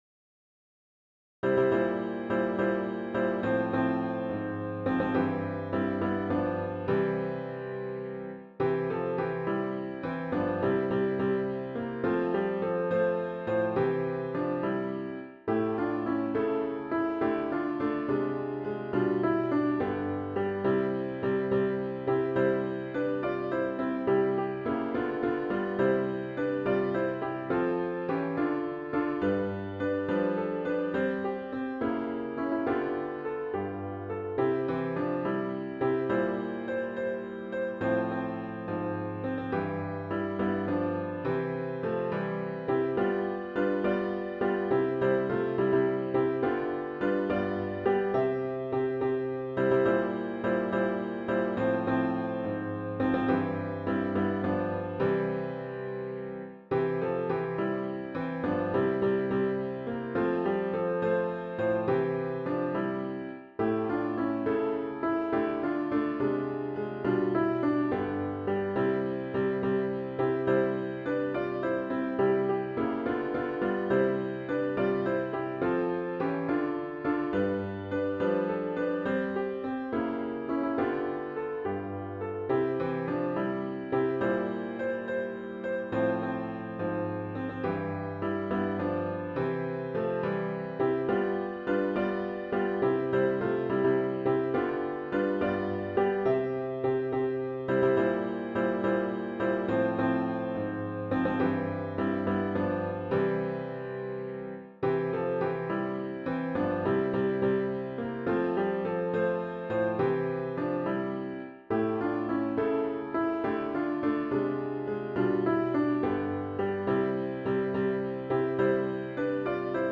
HYMN “His Eye Is on the Sparrow” StF 2146